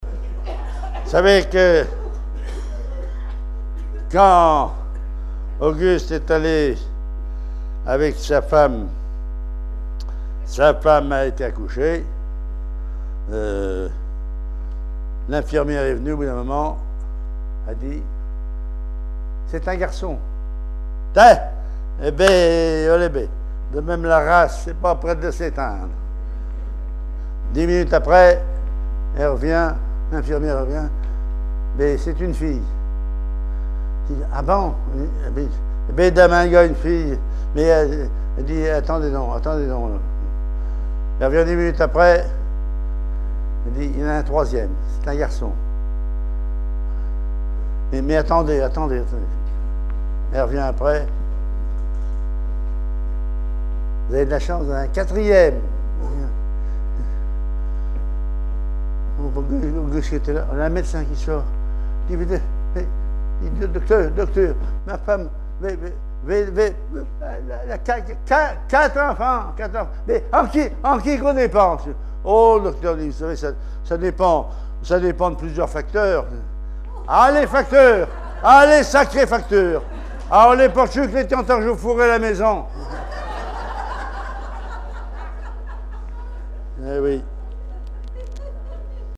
humour
Langue Patois local
Genre sketch